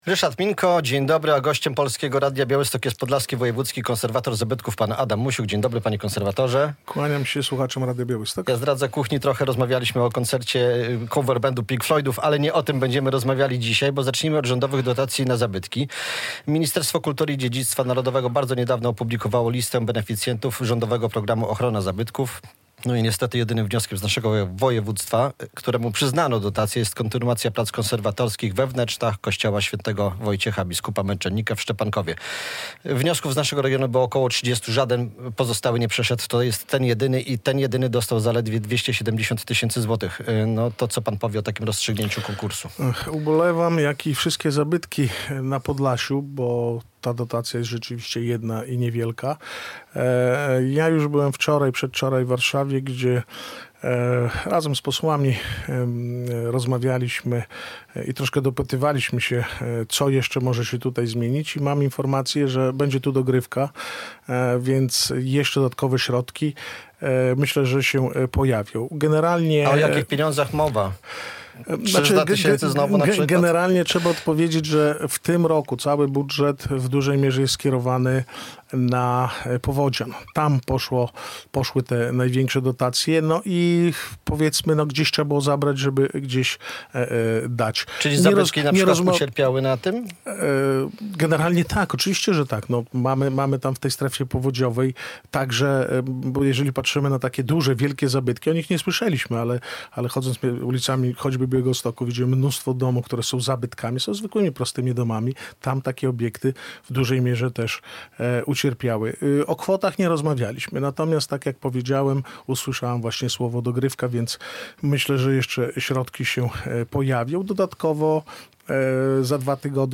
Radio Białystok | Gość | Adam Musiuk - Podlaski Wojewódzki Konserwator Zabytków